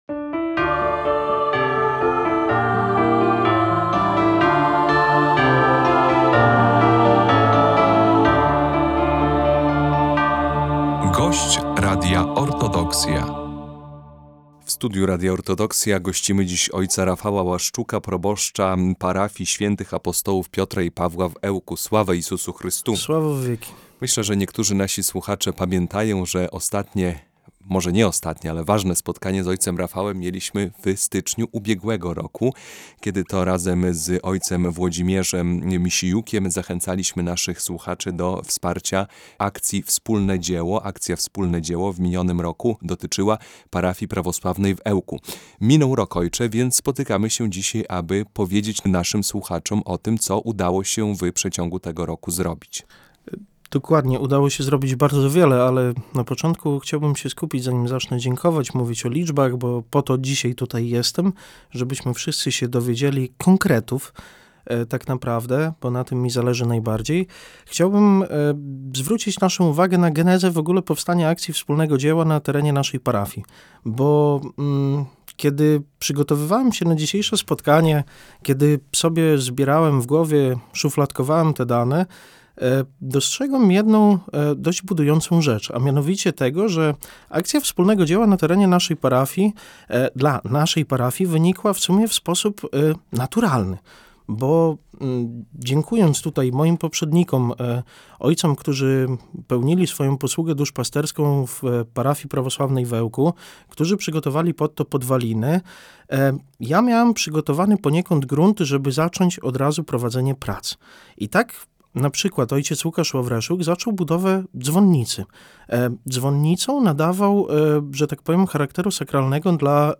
Podsumowanie akcji Wspólne Dzieło 2025 - rozmowa
którego gościliśmy w naszym studiu.